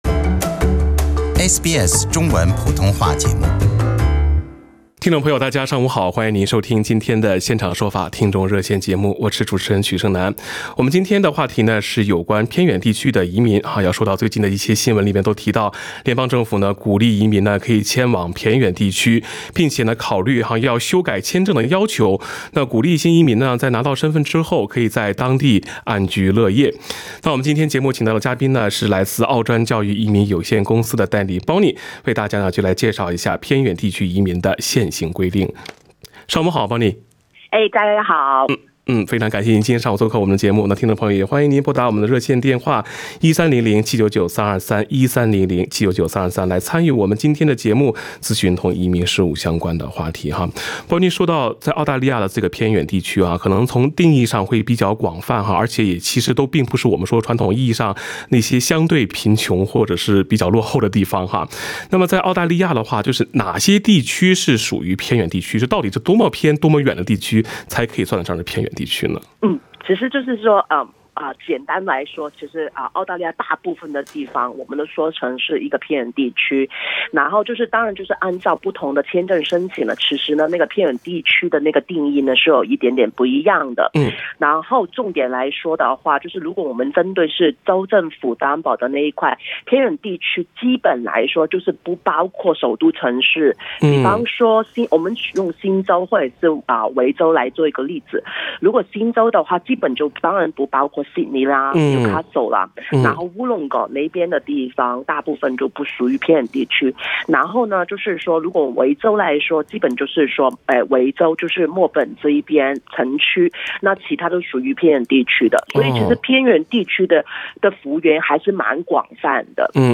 《现场说法》听众热线节目逢周二上午8点30分至9点播出。